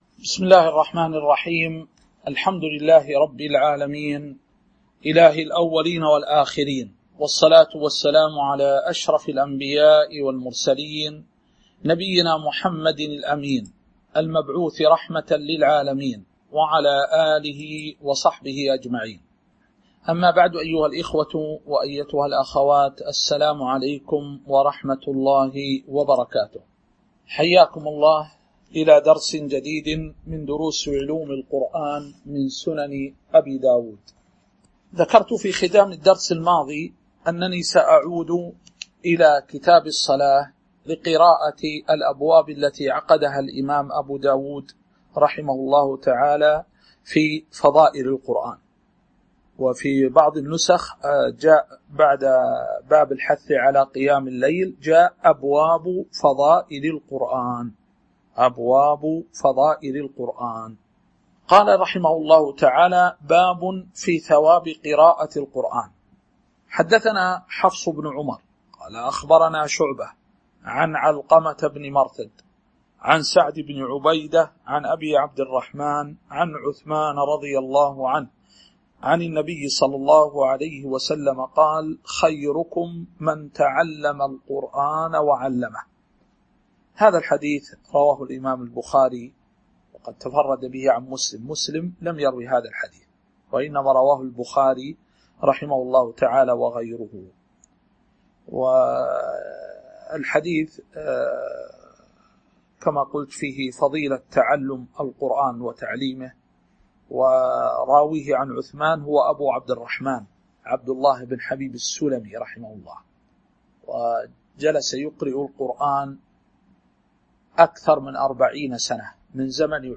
تاريخ النشر ٢٣ ذو الحجة ١٤٤٢ هـ المكان: المسجد النبوي الشيخ